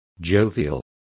Complete with pronunciation of the translation of jovial.